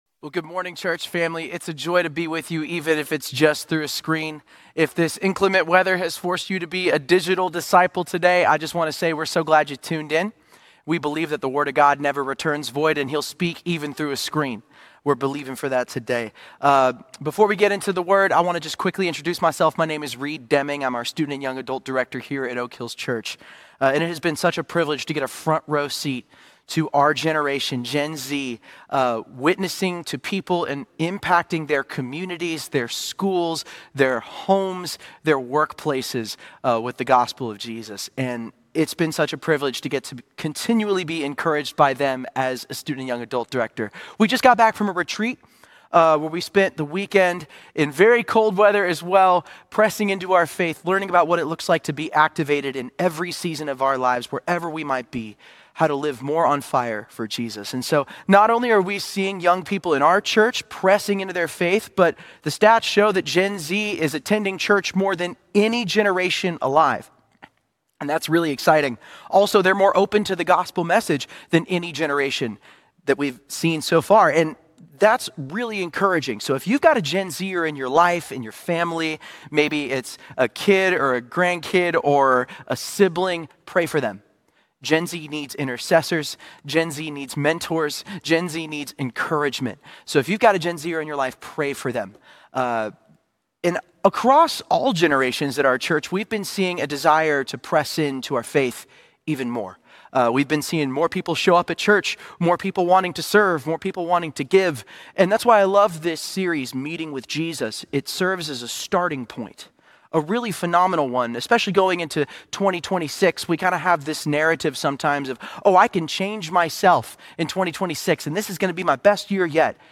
Week 4 in the Series Meeting With Jesus. A message